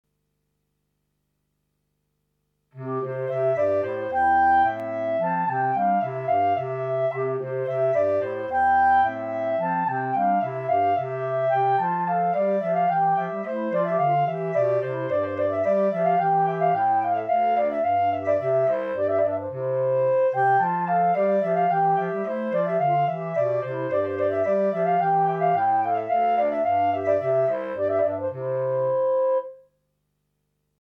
minus Bass Clarinet